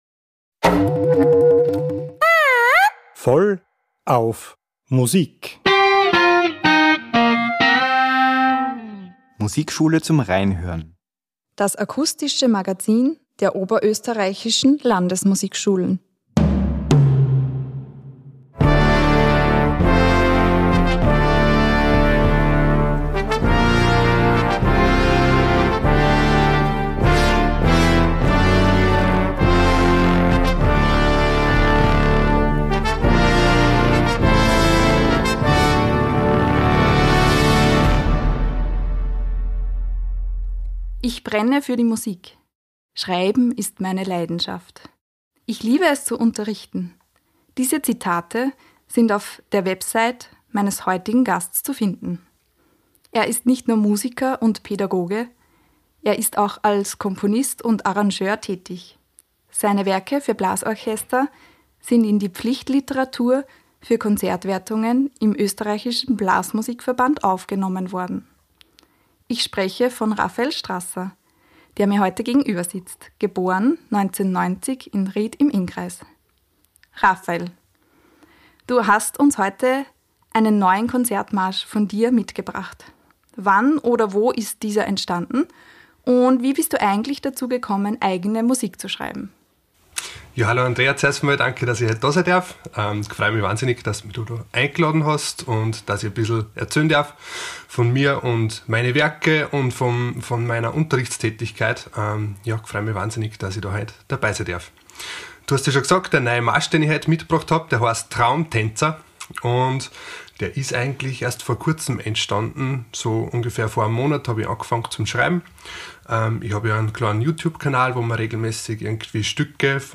Ein inspirierendes Gespräch über Musik, Leidenschaft und das ständige Dranbleiben am eigenen Weg.